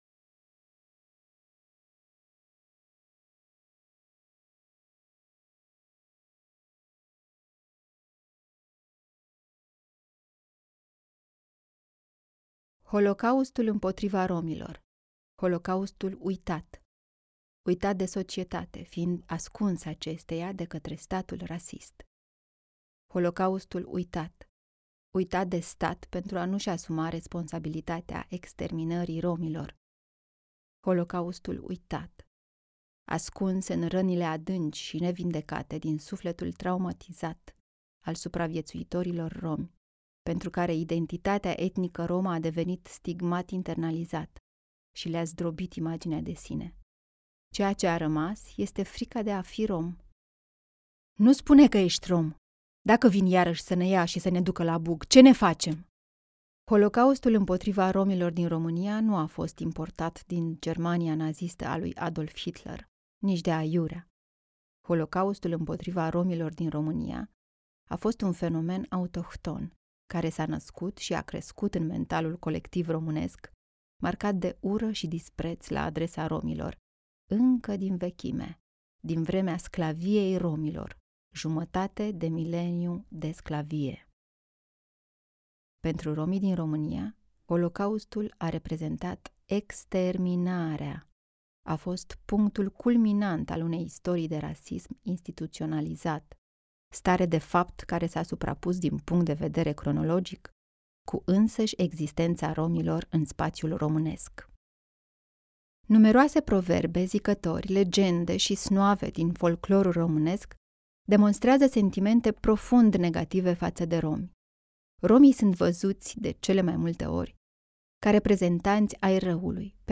Audiobook-RO-Sp-4.mp3